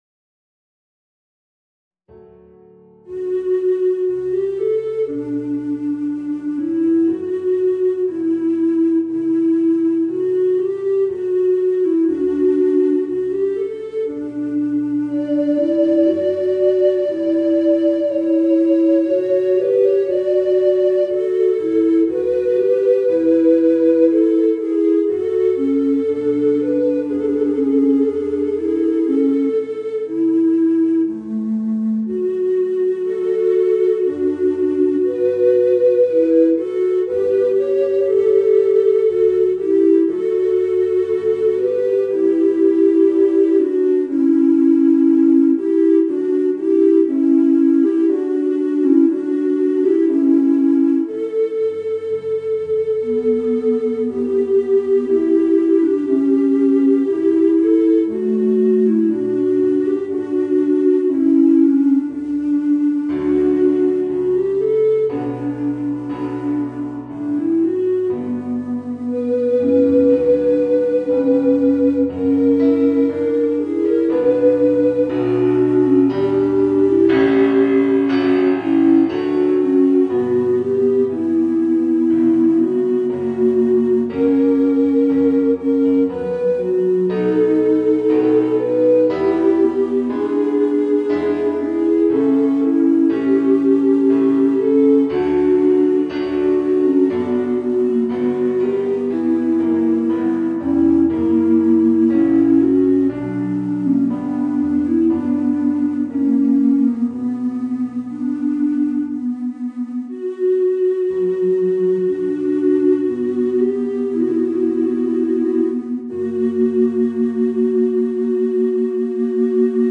Voicing: 2 Bass Recorders and Piano